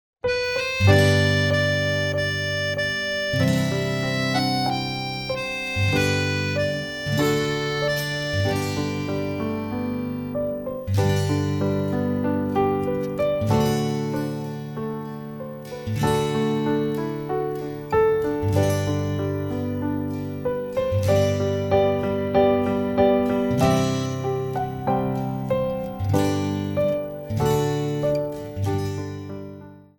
für eine oder zwei Sopranblockflöten
Besetzung: 1-2 Sopranblockflöten